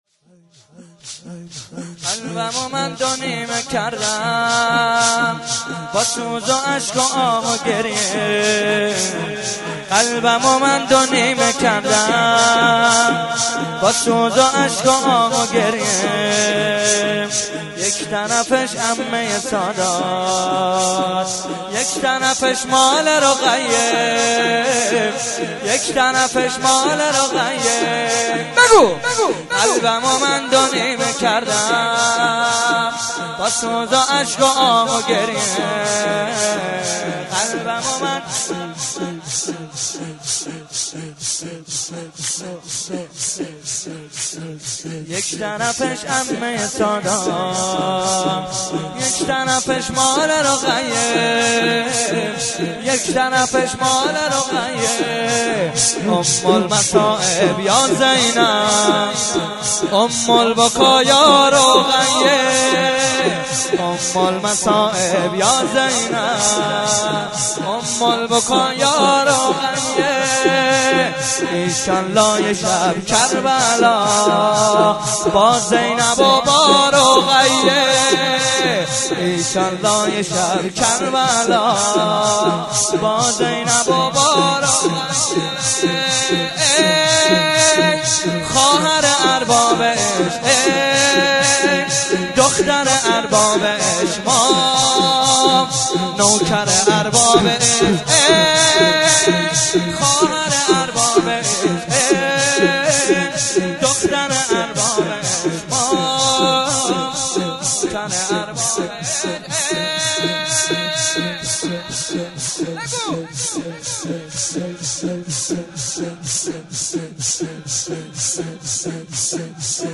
04.sineh zani.mp3